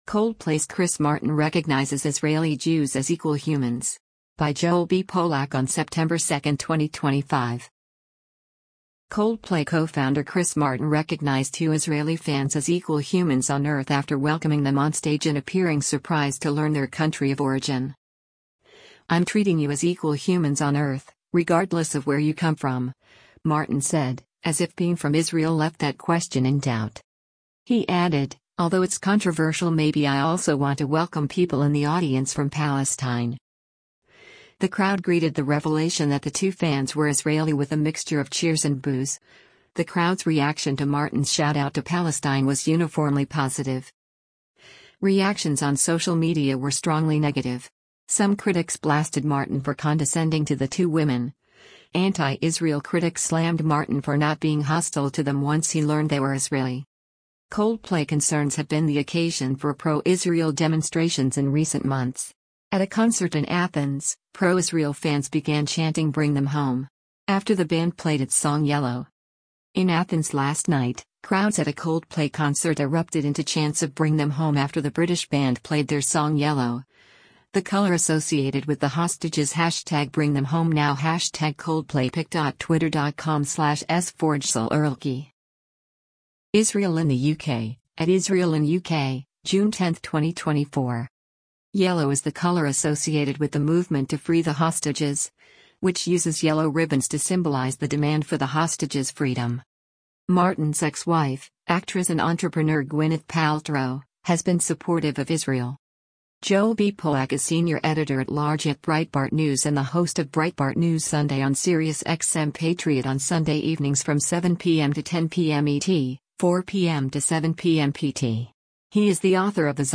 The crowd greeted the revelation that the two fans were Israeli with a mixture of cheers and boos; the crowd’s reaction to Martin’s shout-out to “Palestine” was uniformly positive.